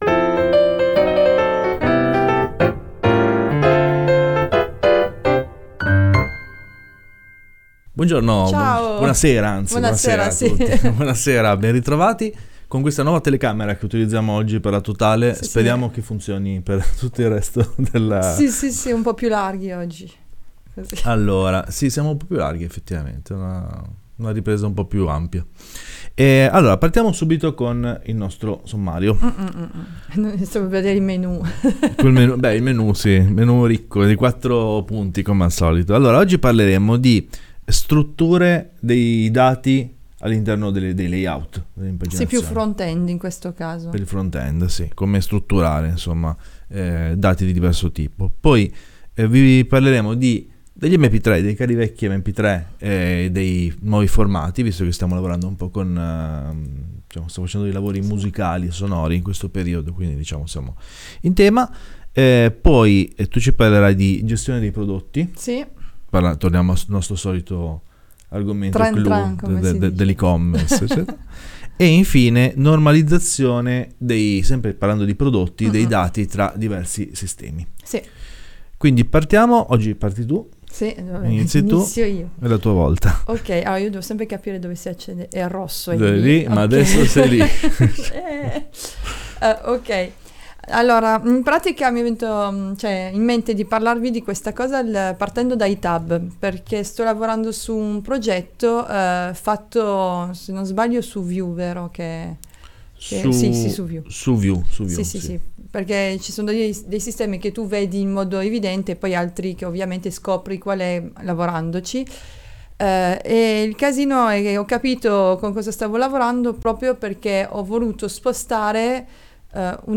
La “week in review” in diretta dalla nostra sede di Torino.